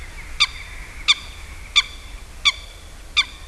Nelle ore notturne è facile sentir risuonare il suo buffo
folaga.wav